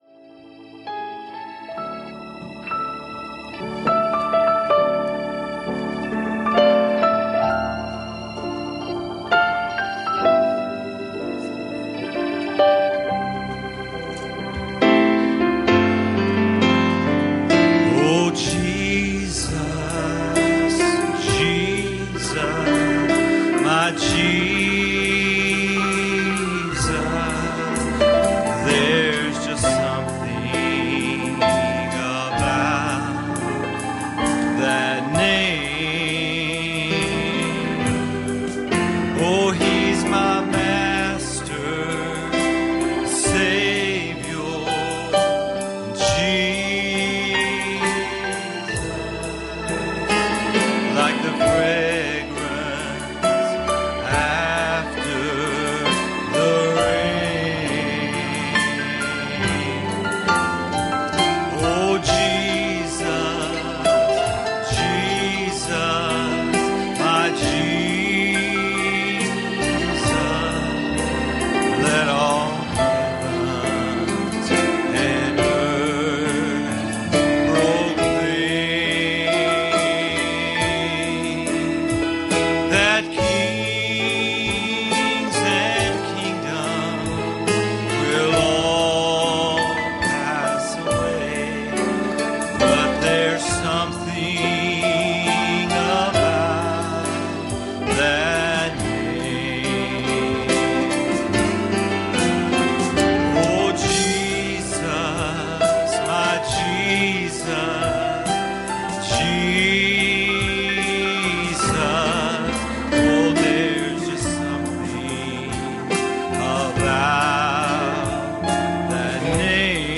Passage: Matthew 10:25 Service Type: Sunday Morning